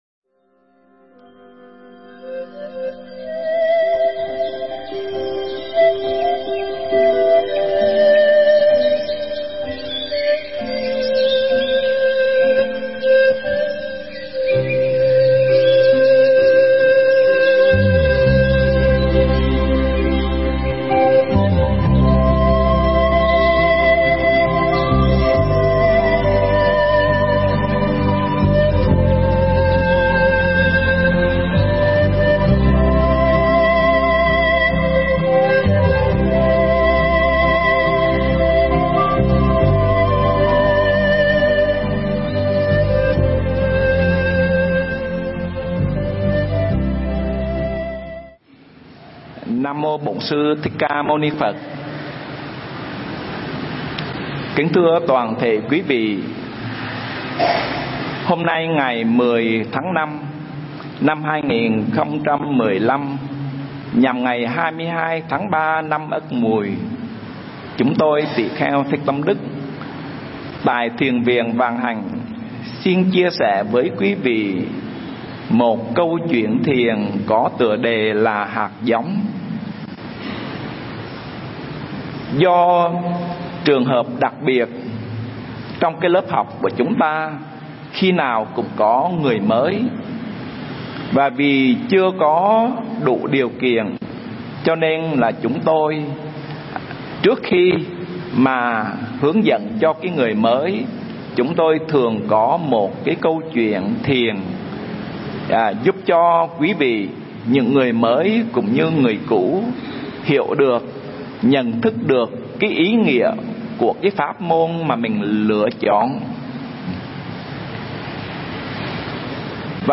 Mp3 Pháp thoại Hạt Giống